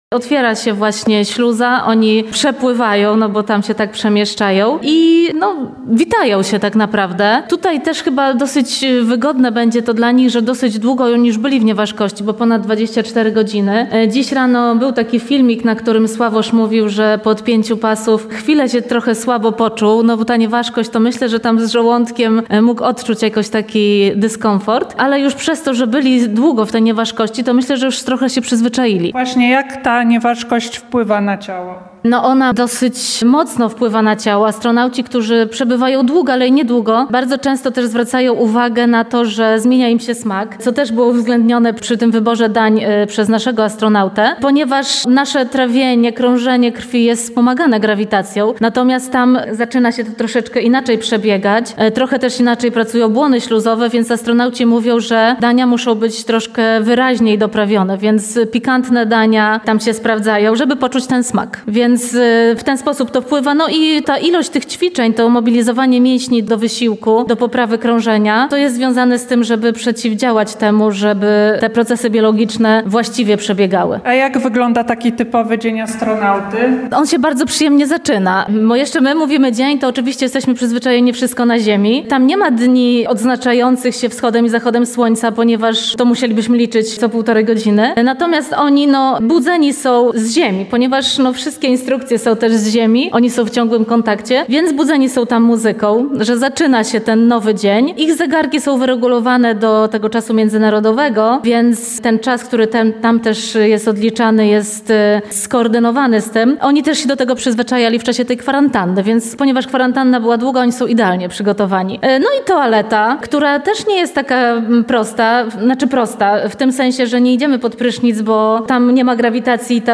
Naszej reporterce opowiedziała między innymi o życiu na stacji.